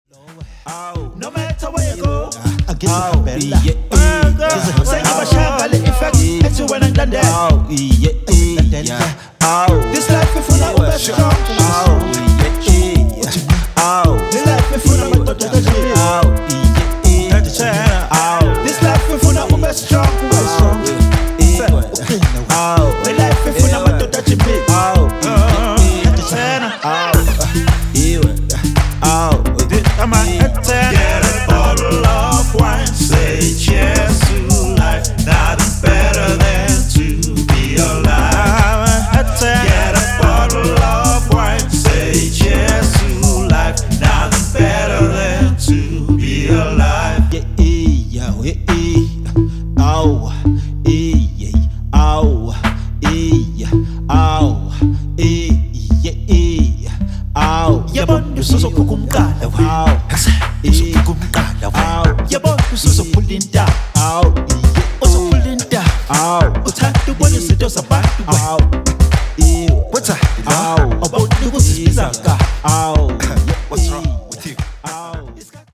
Zulu staccato rap and R&B stylings